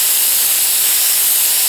soda_loop.ogg